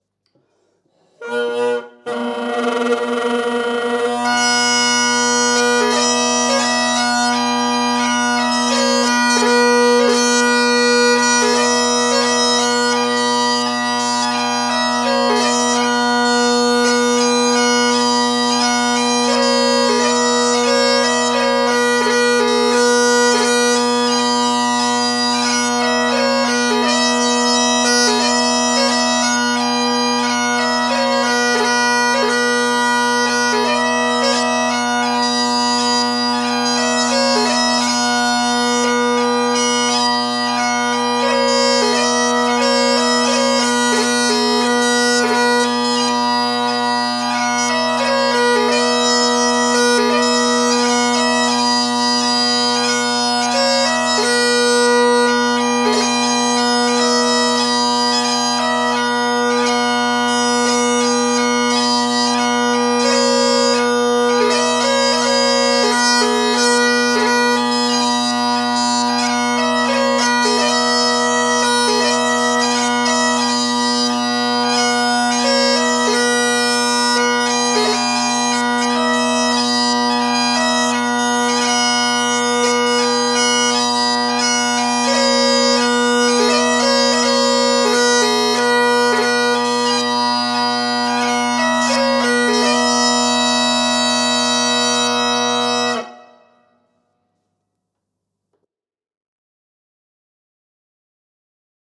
Marvellous march merits ‘Mallaig Harbour Authority’ moniker
The two-part 4/4 was chosen as the winner out of 16 entries
The tune had to accommodate the A-mixolydian (flattened 7th) scale of the Highland pipes.